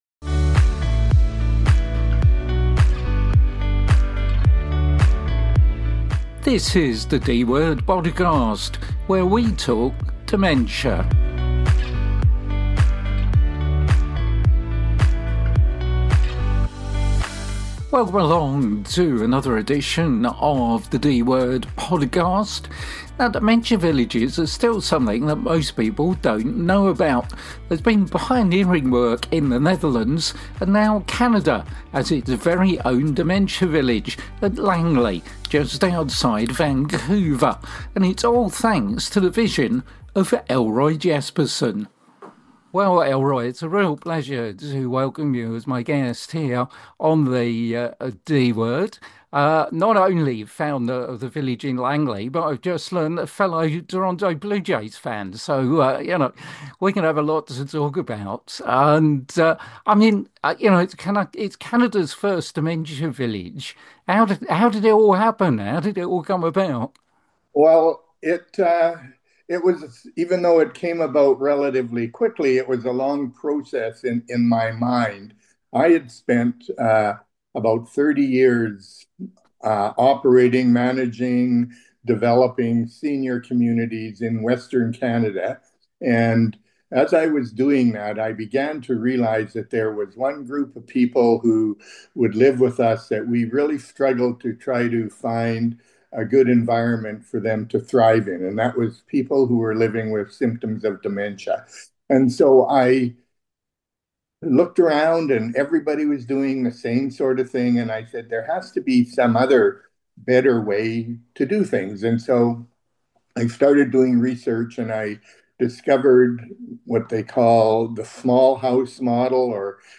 The ‘D’ Word is the UK’s only dementia-focused radio show.